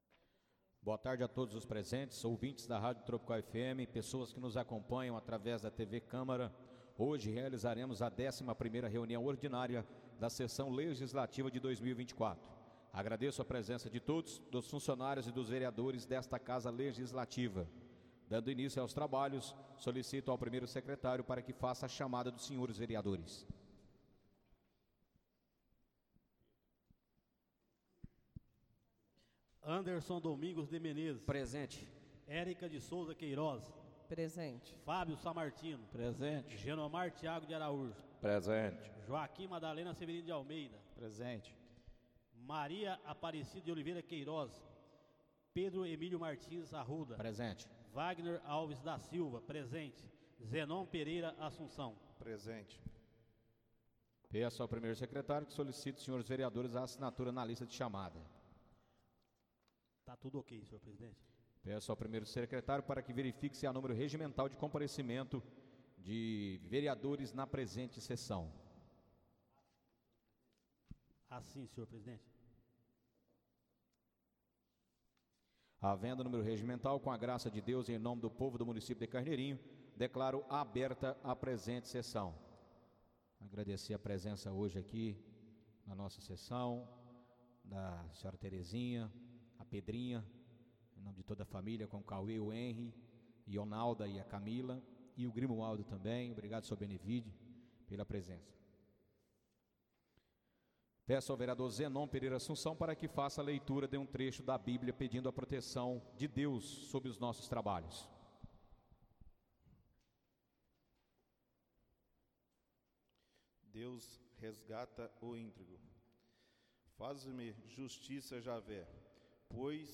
Áudio da 11.ª reunião ordinária de 2024, realizada no dia 1 de Julho de 2024, na sala de sessões da Câmara Municipal de Carneirinho, Estado de Minas Gerais.